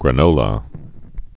(grə-nōlə)